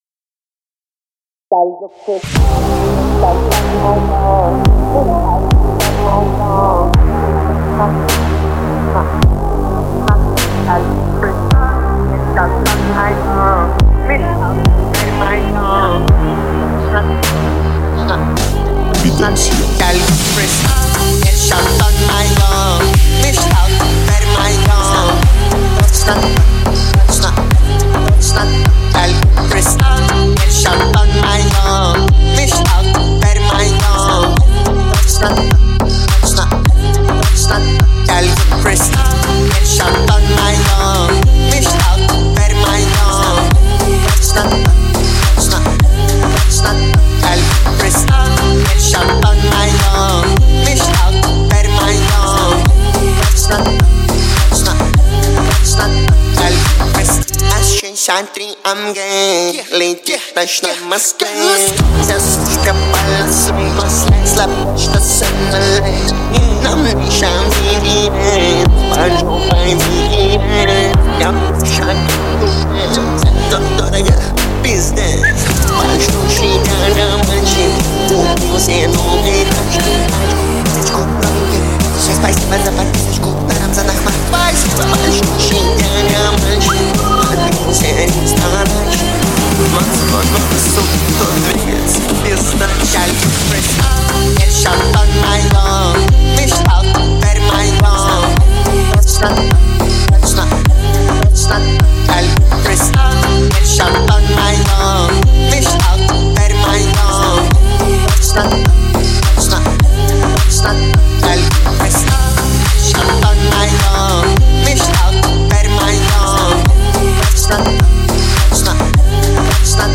это энергичный трек в жанре хип-хоп и рэп